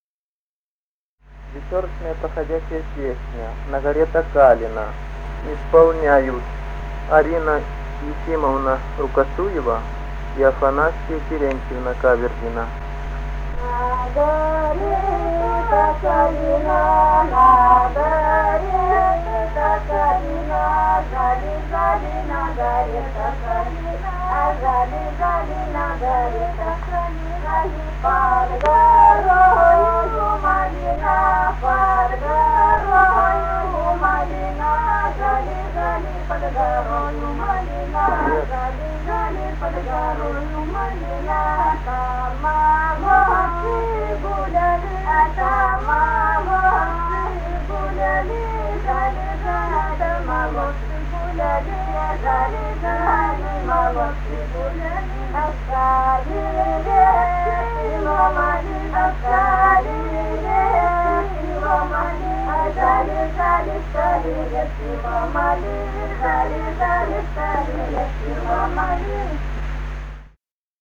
Русские народные песни Красноярского края.
«На горе-то калина» (вечёрочная «проходяча»). с. Яркино Богучанского района.